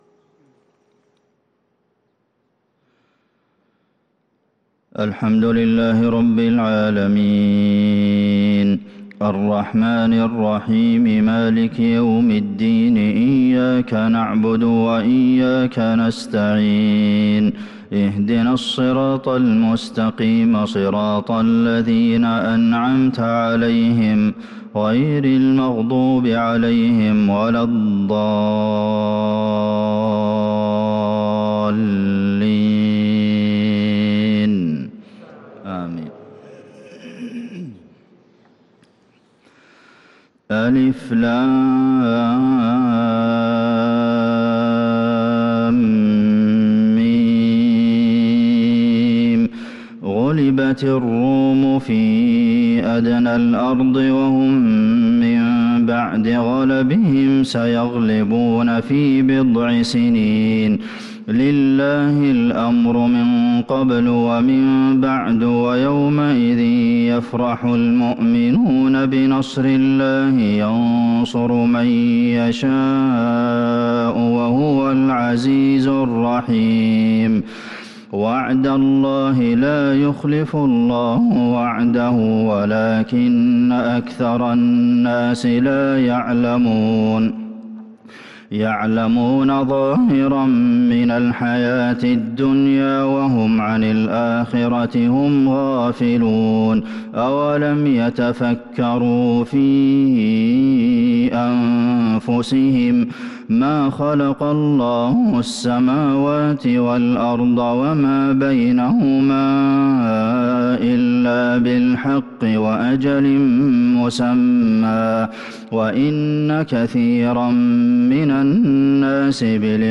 صلاة الفجر
تِلَاوَات الْحَرَمَيْن .